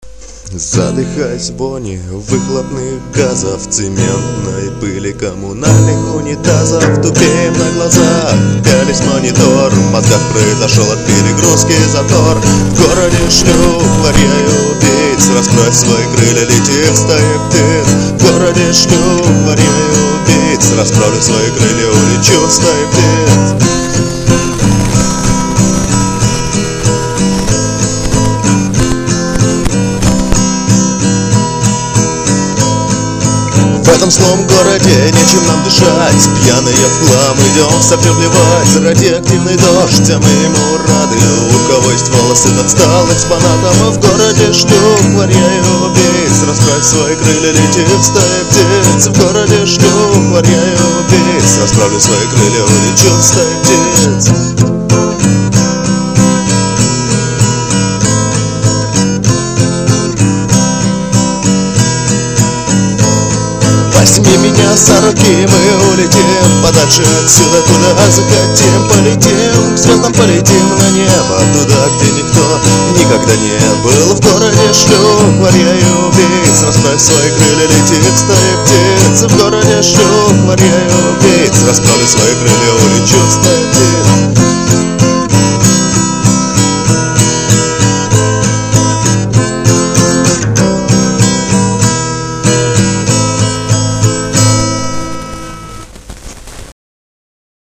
Etaji-Krilya_acoustic.mp3